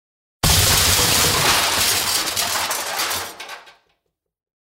Вы можете слушать и скачивать мощные удары по стенам, грохот бронзового наконечника и другие эффекты, воссоздающие атмосферу средневековых битв.
Грохот разрушения тараном ворот или иного объекта